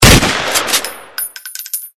Dzwonek - Strzał z shot guna
Dźwięk strzału z shot guna.
strzal-z-shot-guna.mp3